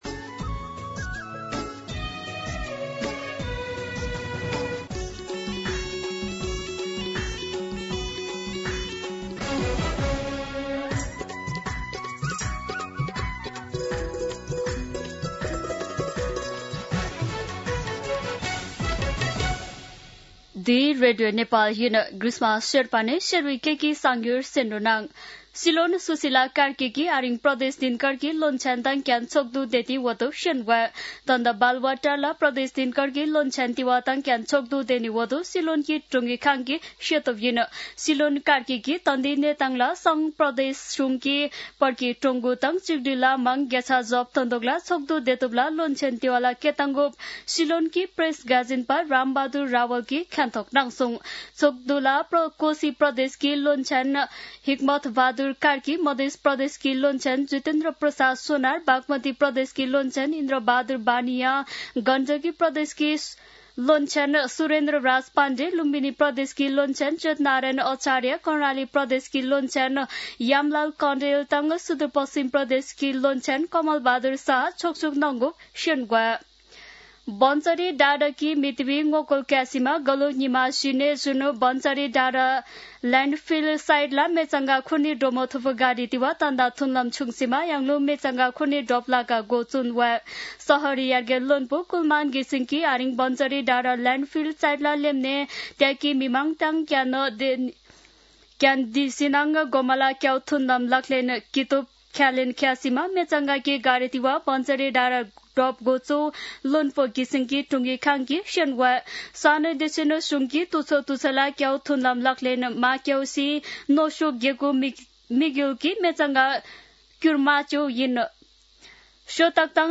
शेर्पा भाषाको समाचार : १६ कार्तिक , २०८२
Sherpa-News.mp3